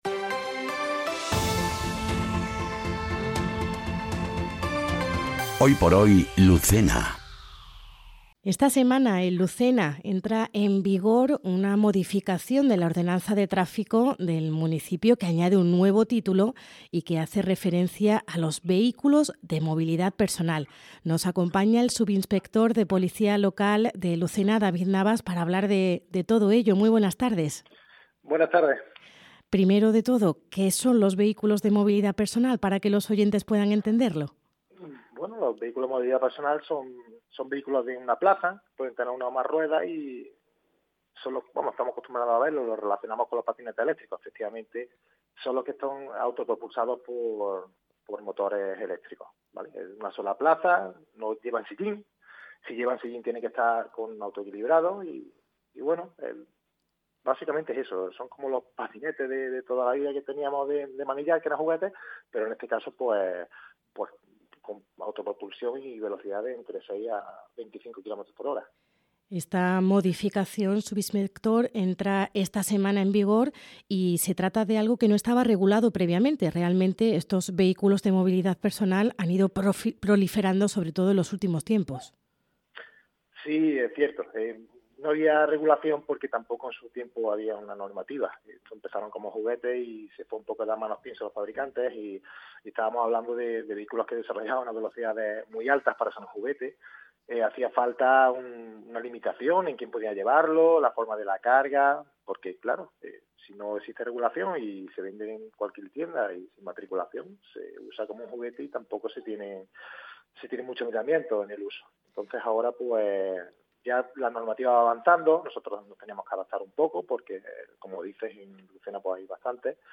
ENTREVISTA | Nueva normativa sobre Vehículos de Movilidad Personal